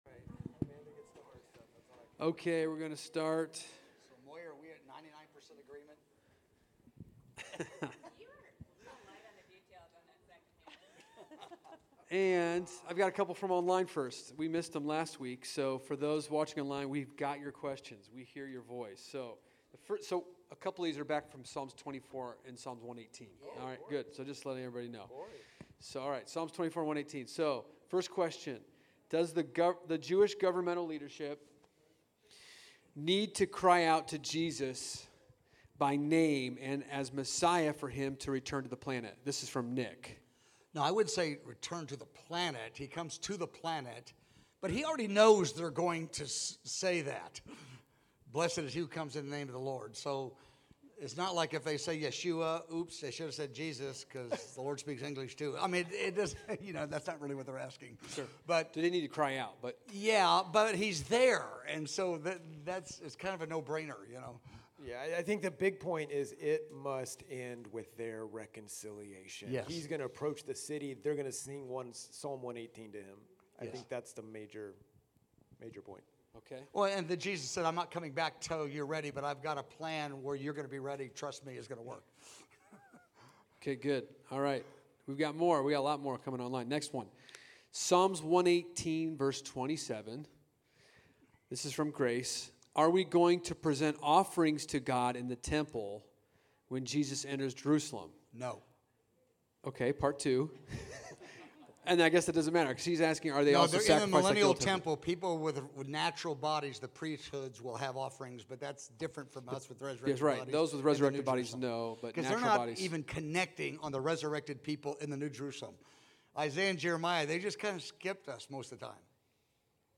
EVENTS: Encounter God Service (2020)